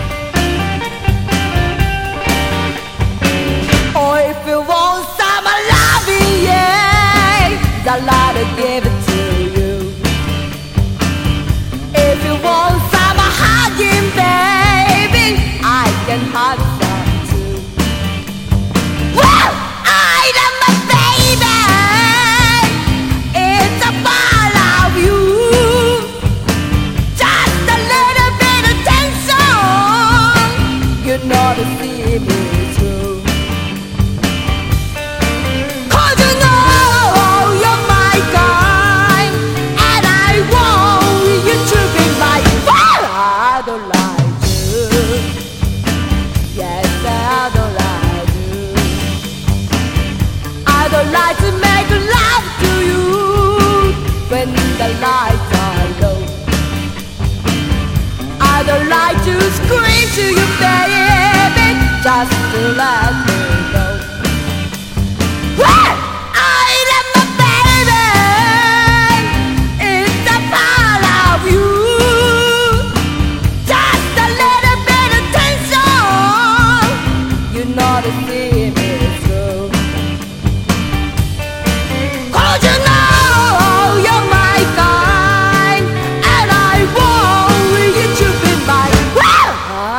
GARAGE PUNK / INSTRO / 90'S GARAGE PUNK / JAPANESE PUNK
四日市で結成されたガレージ・ギター・インスト・トリオ！
焦げ臭いファズ・ギターとドカドカ・ブンブン唸るリズム隊をもってアグレッシヴにプレイしています！